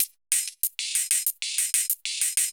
Index of /musicradar/ultimate-hihat-samples/95bpm
UHH_ElectroHatA_95-04.wav